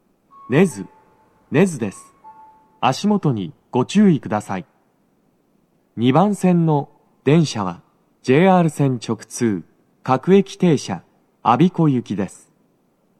足元注意喚起放送が付帯されており、粘りが必要です。
到着放送1